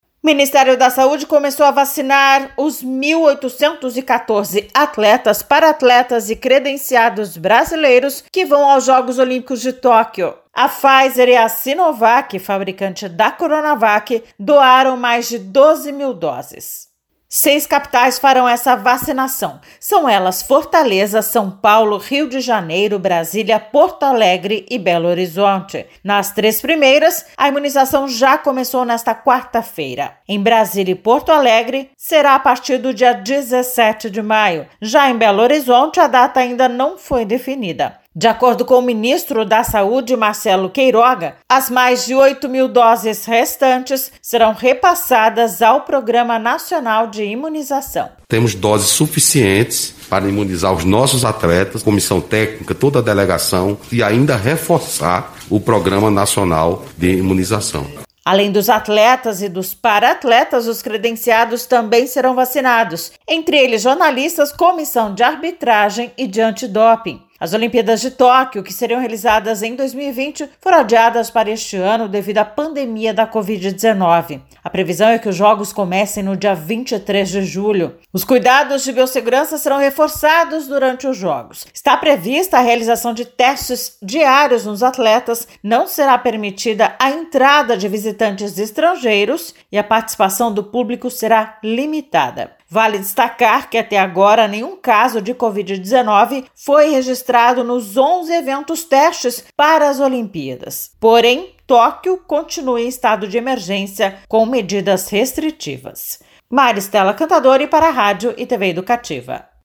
Confira as informações com a repórter